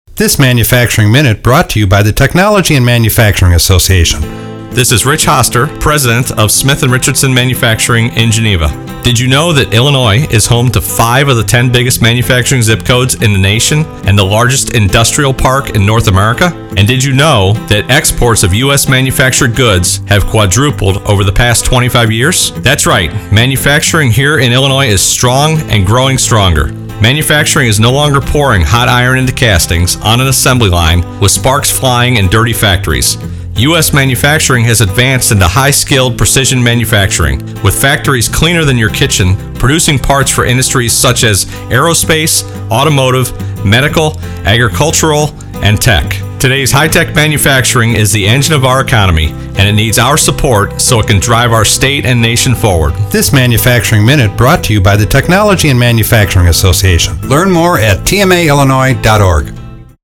TMA releases second radio ad promoting IL manufacturing
SCHAUMBURG – Have you been driving over the past few weeks with the dial set on WBBM 780 AM or WIND 560 AM and heard this?